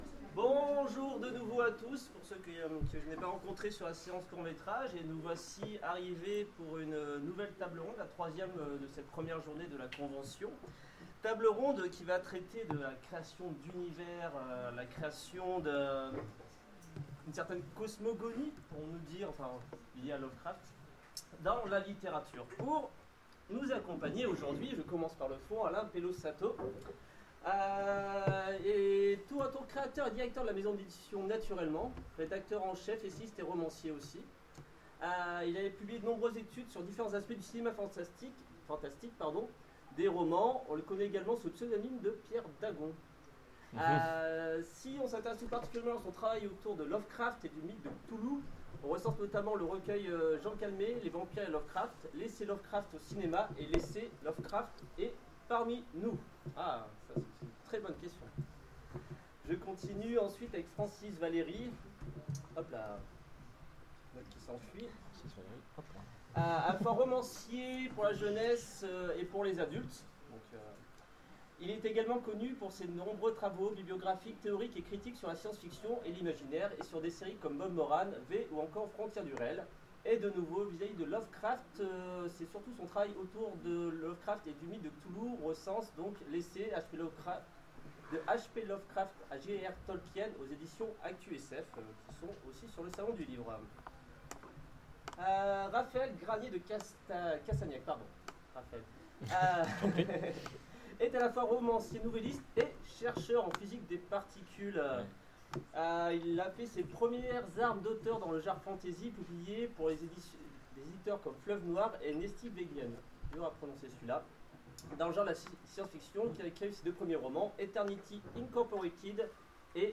Necronomicon 2015 : Conférence Construire une mythologie en littérature